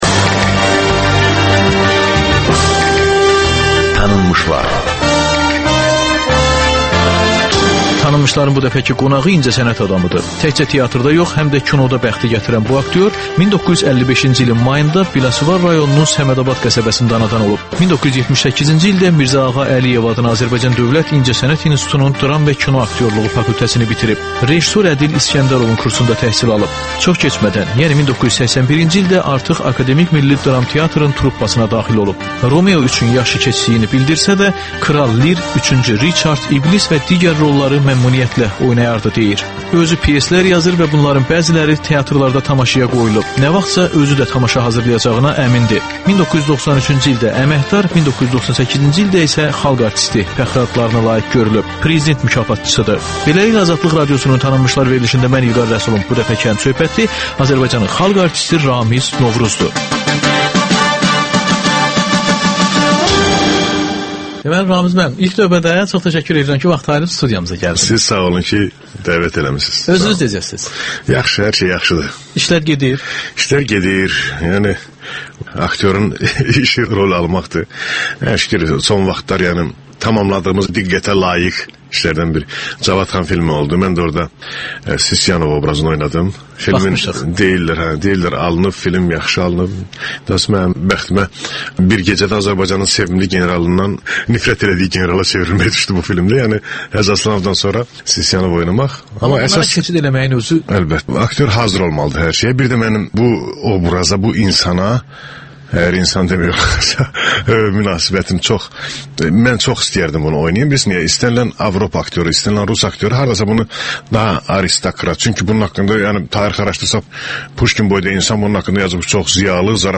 Xalq artisti Ramiz Novruzla söhbət